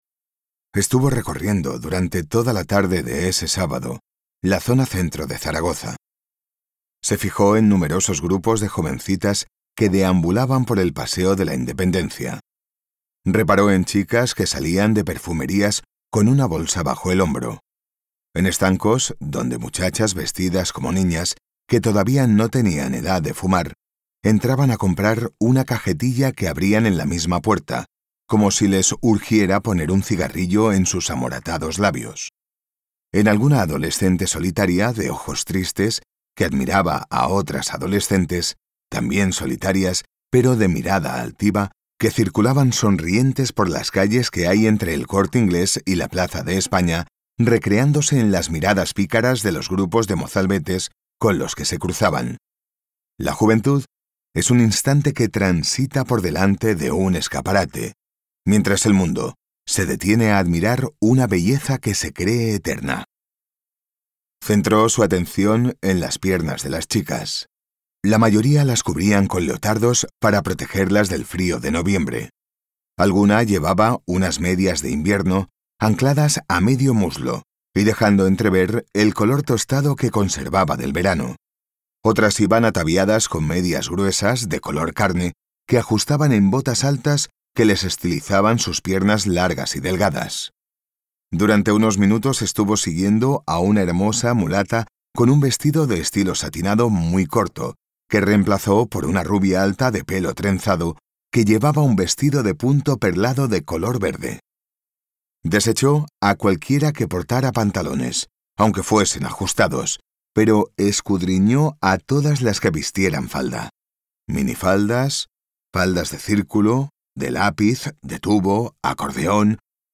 Audiolibros Novela policíaca - Sonolibro